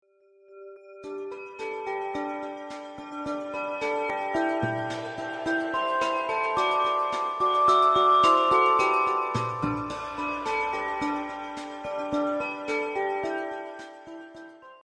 Короткая праздничная музыкальная вставка